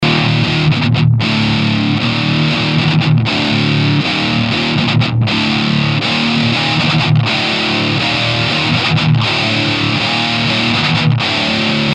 Не подскажите где можно найти звук Clap , Cajon .